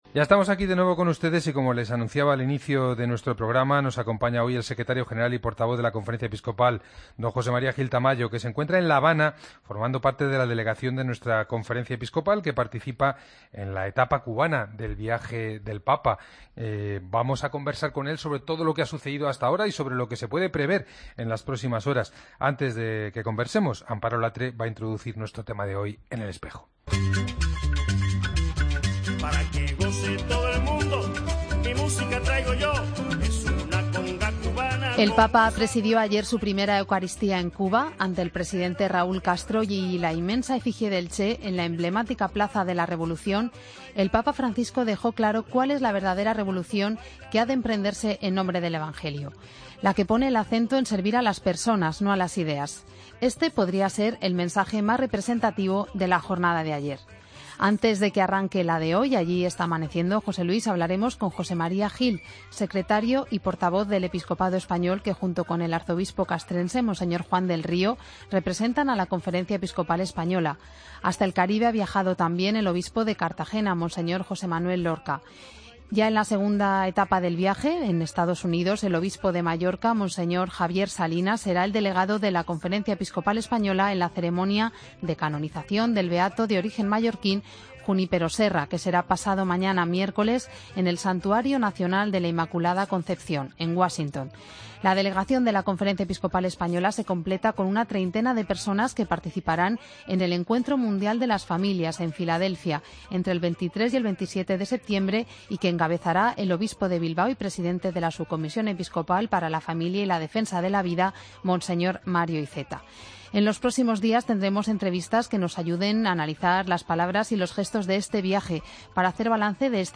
Escucha la entrevista a don José María Gil Tamayo en El Espejo
AUDIO: Nos atiende desde La Habana, donde acompaña al Santo Padre.